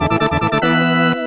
sound_menu_start.wav